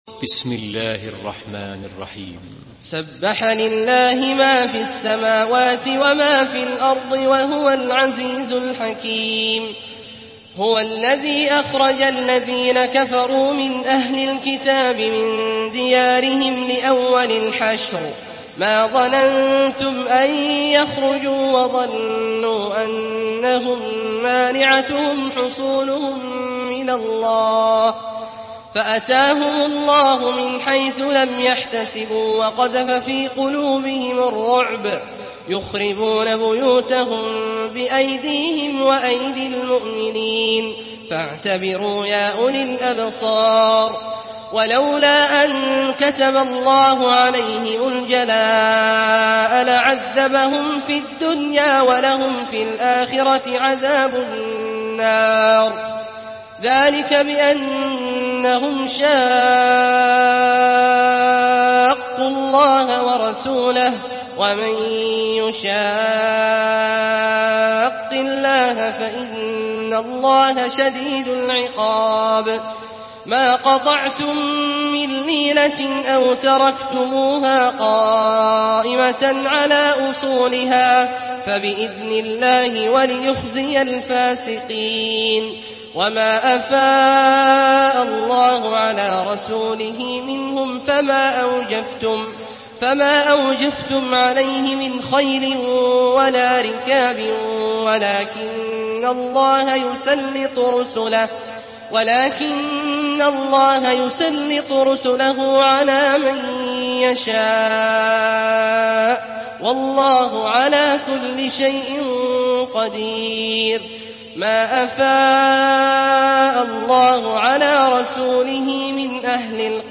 سورة الحشر MP3 بصوت عبد الله الجهني برواية حفص
مرتل